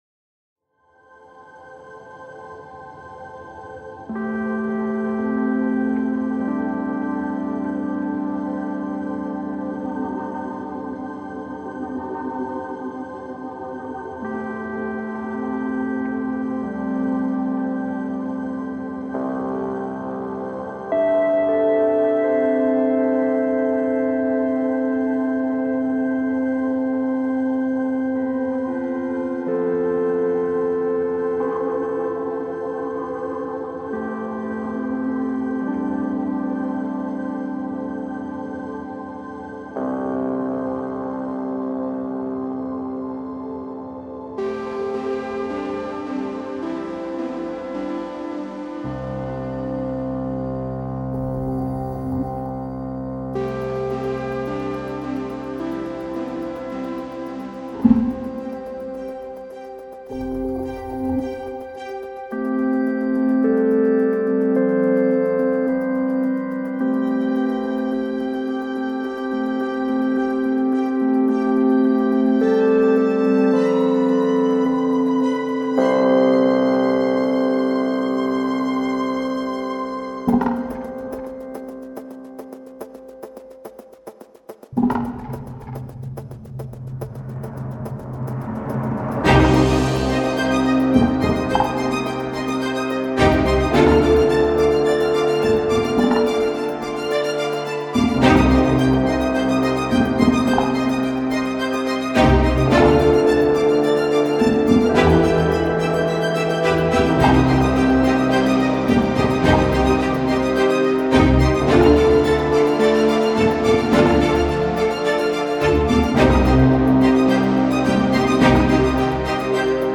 它包含了 50 个不同的音色，包括声音设计、鼓组和拟声，这个乐器非常适合音乐家和声音设计师制作从海洋颂歌、19 世纪和20 世纪初的时期风格音乐，一直到电子舞曲和恐怖/氛围音乐。它使用了一台建于 1865 年的加拿大维多利亚风格的风琴作为声源。